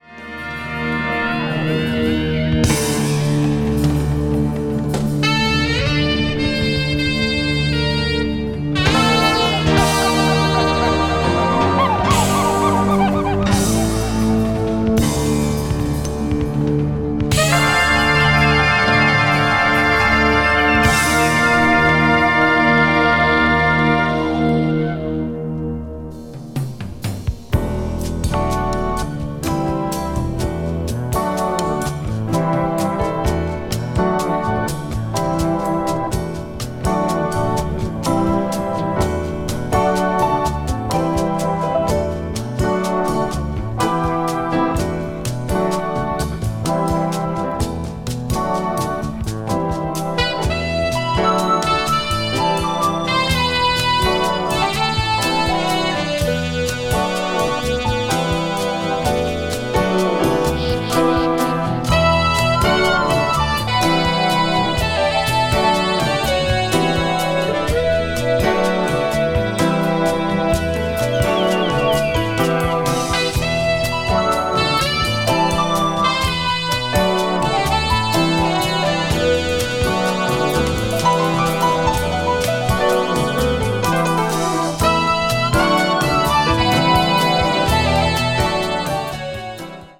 media : EX/EX-(わずかにチリノイズが入る箇所あり,B1前半:一部軽いチリノイズあり)
contemporary jazz   crossover   fusion   new age jazz